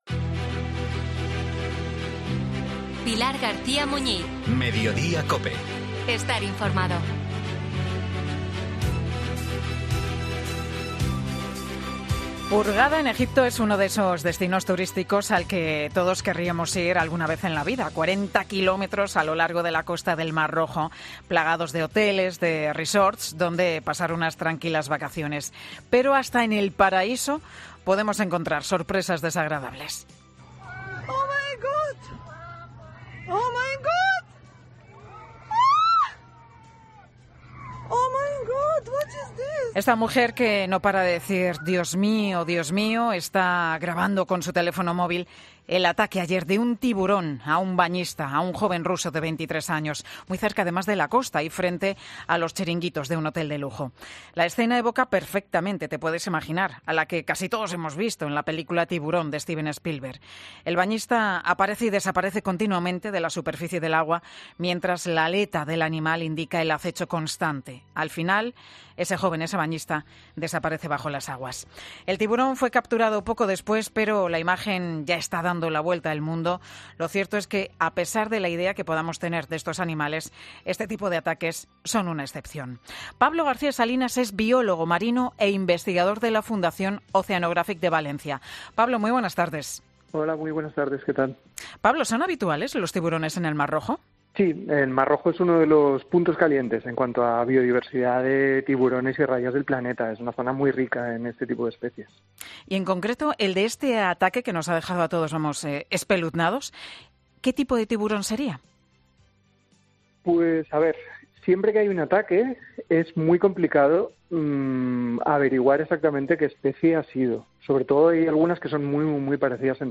En Mediodía COPE, un biólogo marino tranquiliza a los bañistas ante ataques de tiburones en nuestros mares